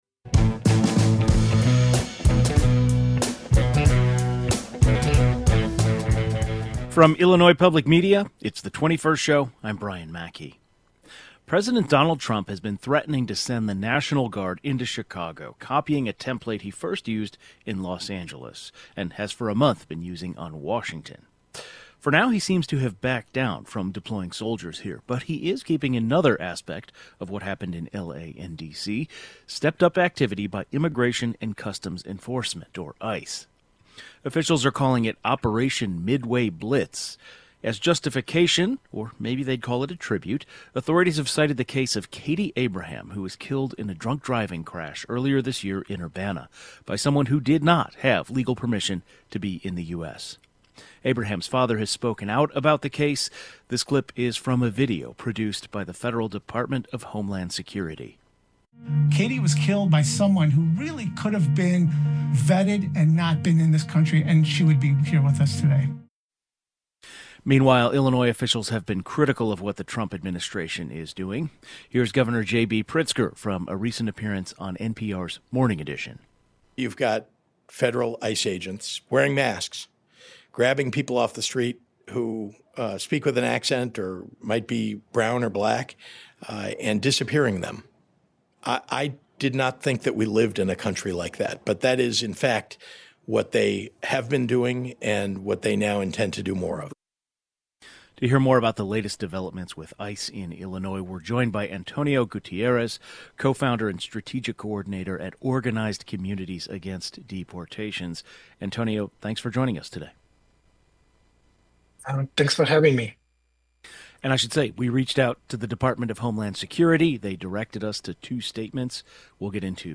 The Department of Homeland Security has launched “Operation Midway Blitz" in Illinois. Officials say the mission of the operation is to target criminals, who are not authorized to be in the U.S. However, critics say the ICE raids are not targeting criminals, but latino communities as a whole. The 21st Show is Illinois' statewide weekday public radio talk show, connecting Illinois and bringing you the news, culture, and stories that matter to the 21st state.